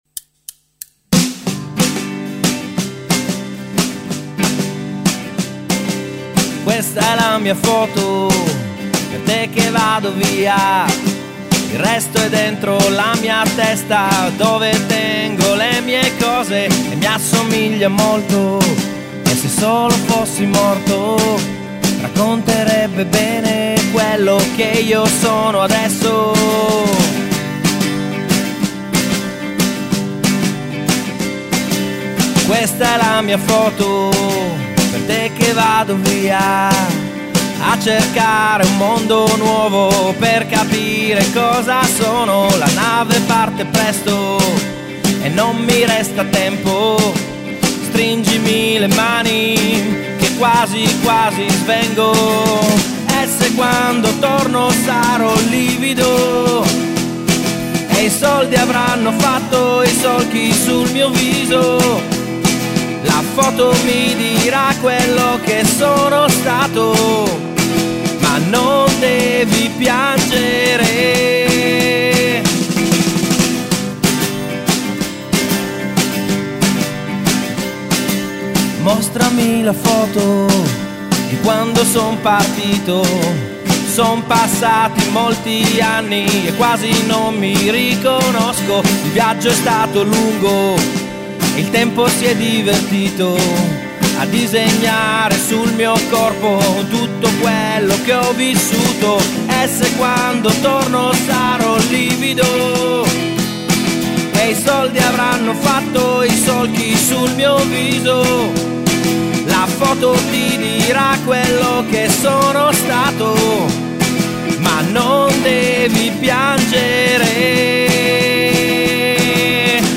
hanno eseguito dal vivo in versione acustica